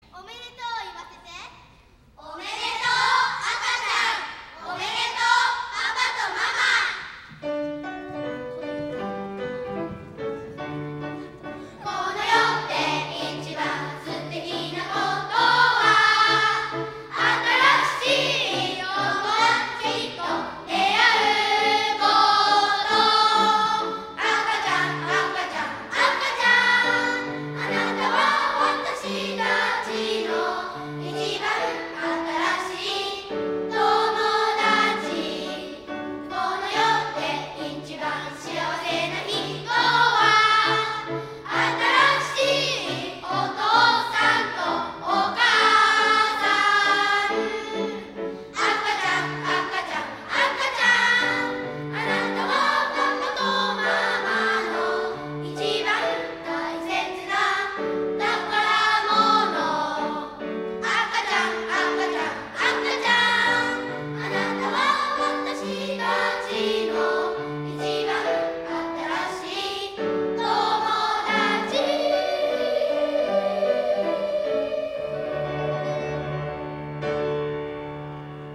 11月18日、藤沢市民会館にて『2015年度　湘南学園小学校音楽会』が開催されました。
歌声は大ホールに響き渡り、素晴らしいハーモニーを奏でました。
劇と歌の融合は、音楽会の発表でも異彩を放ち、色々な仕掛けや迫真の演技と歌声で大いに盛り上がりました。
4年生 学年合唱『ショートショートオペレッタ「海賊船長の子守歌」』